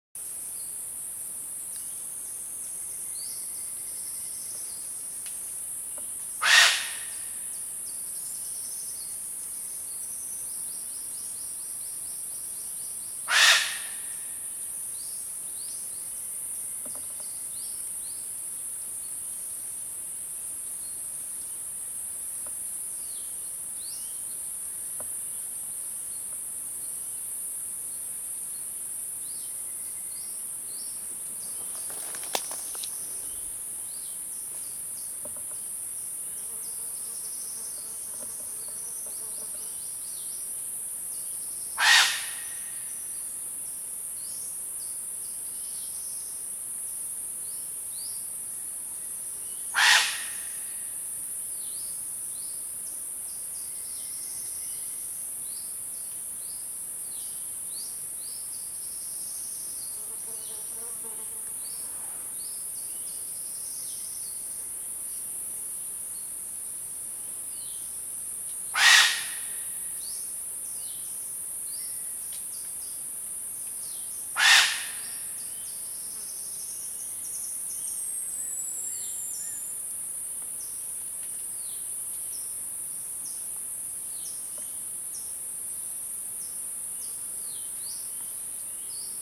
짧은꼬리마카크 (''Macaca arctoides'') 소리 – 깸끄라찬 국립공원